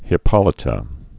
(hĭ-pŏlĭ-tə)